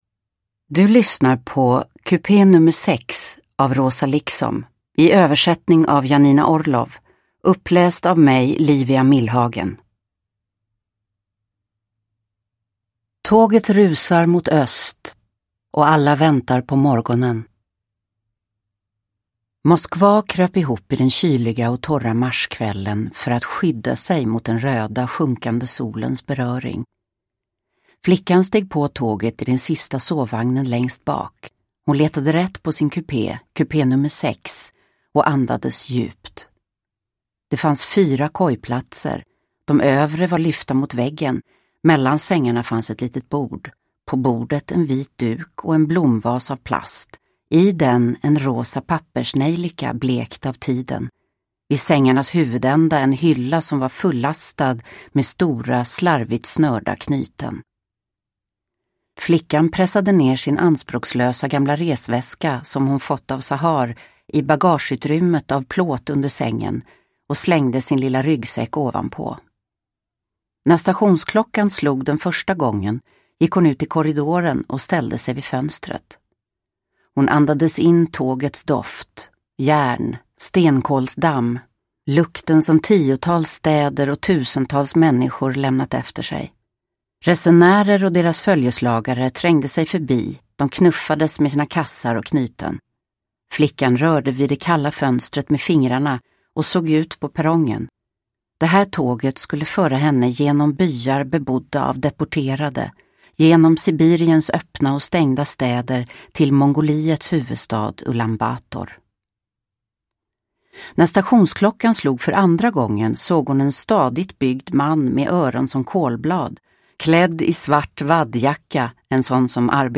Kupé nr 6 – Ljudbok – Laddas ner
Uppläsare: Livia Millhagen